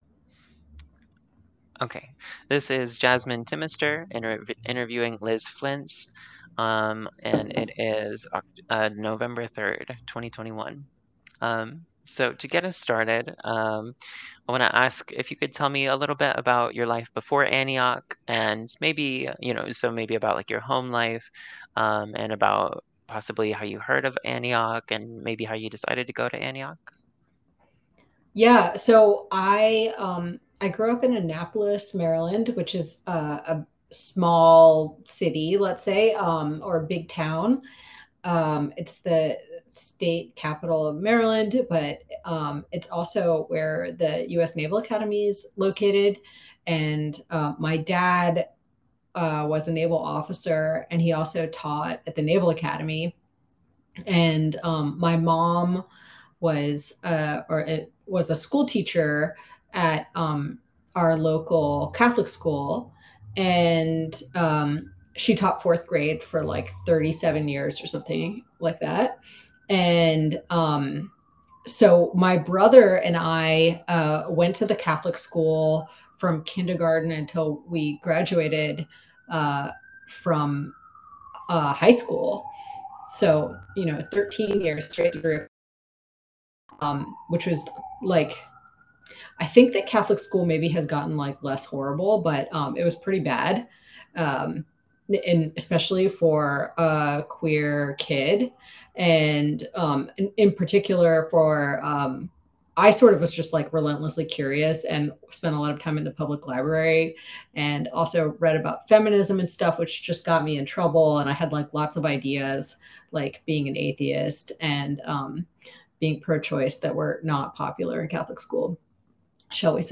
Oral History in the Liberal Arts | LGBTQ+ Experiences at Antioch College